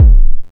Drums12C.mp3